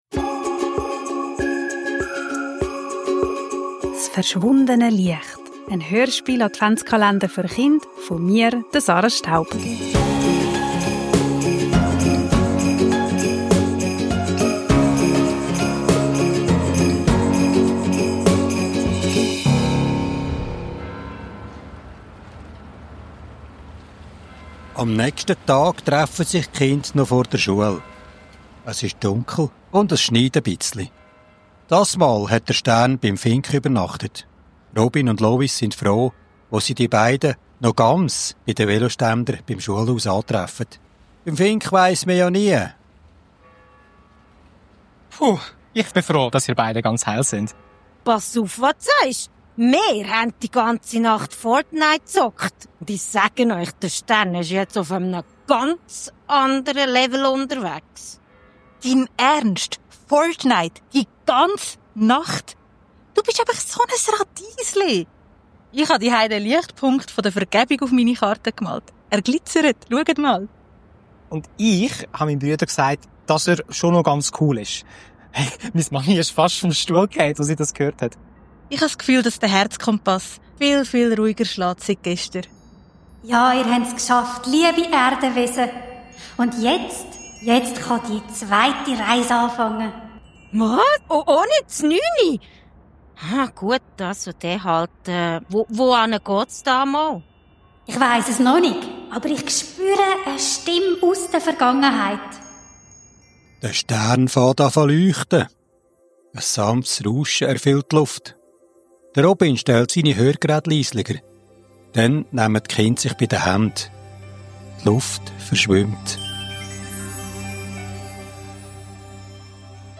Kinder, Advent, Hörspiel, Weihnachten, Kindergeschichte